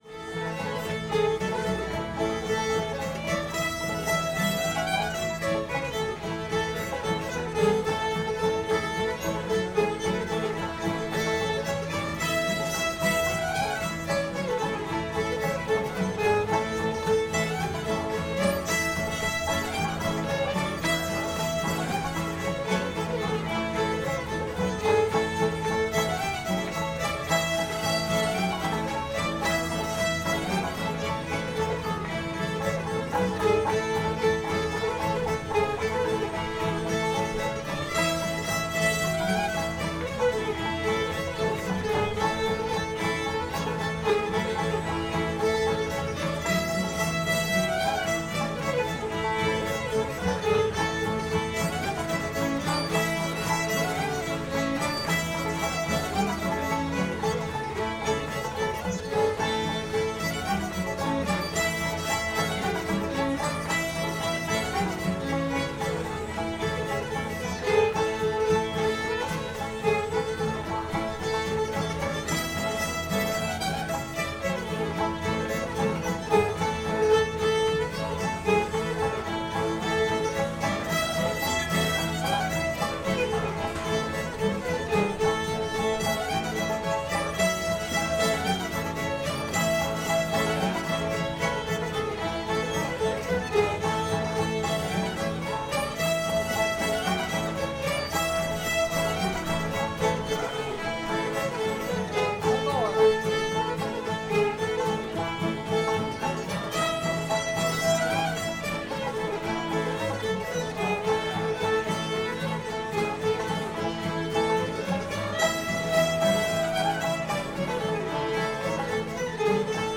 pretty little shoes [A]